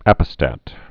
(ăpĭ-stăt)